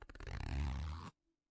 拉链 " zipper3
描述：金属拉链从钱包或裤子被拉开
Tag: 金属 钱包 裤子 拉链 拉链